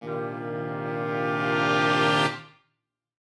Index of /musicradar/gangster-sting-samples/Chord Hits/Horn Swells
GS_HornSwell-Cmin+9sus4.wav